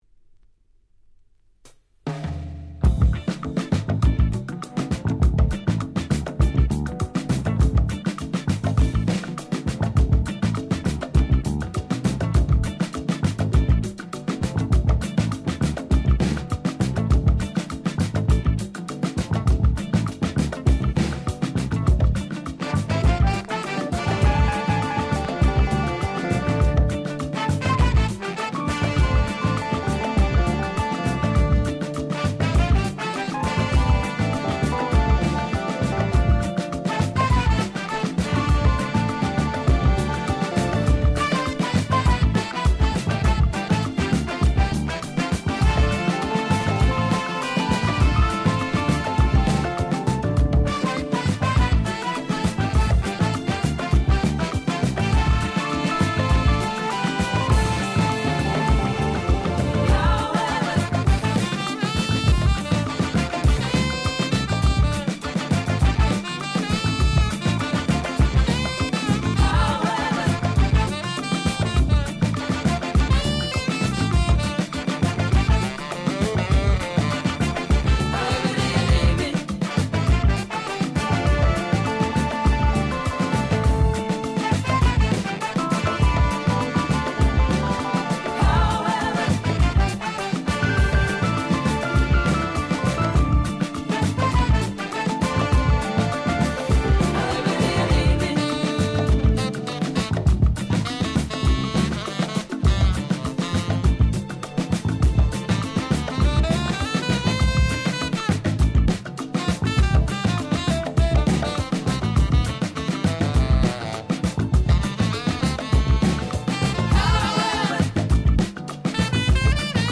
20' Super Nice Remix !!